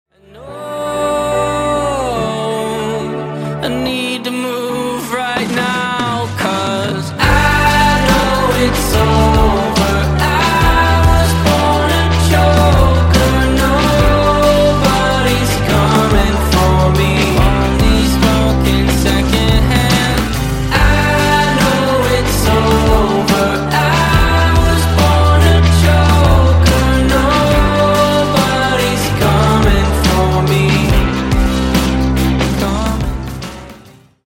Рингтоны Альтернатива
Рок Металл Рингтоны